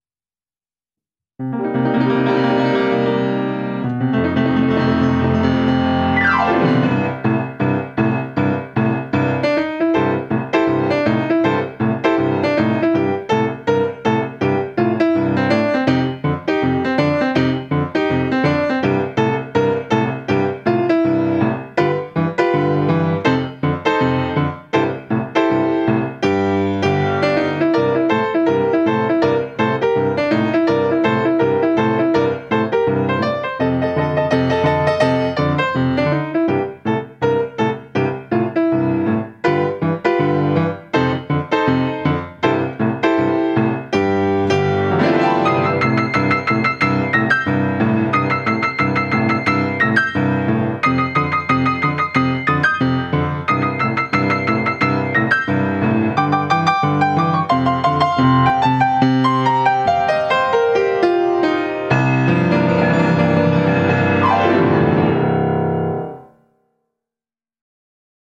original piano solo
Key: C Blues
Time Signature: 4/4 (Swing feel)